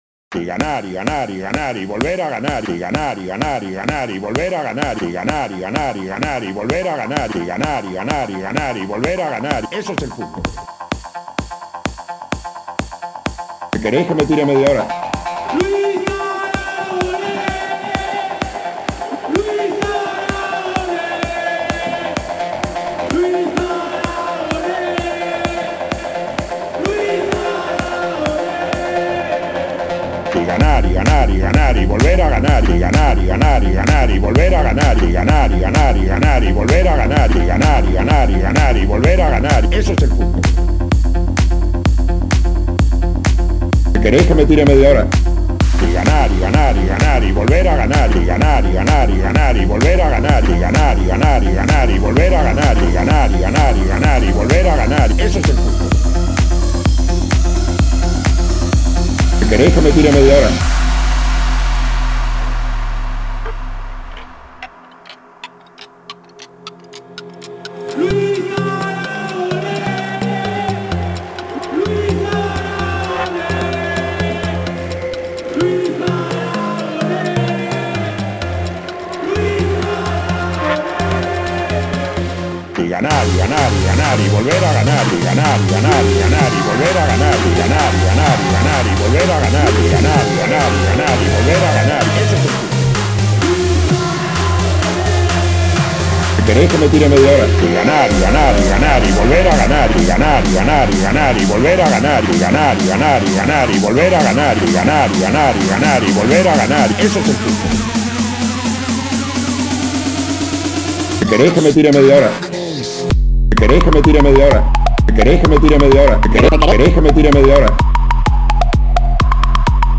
A continuación un homenaje musical al MÁS GRANDE: